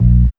4608R BASS.wav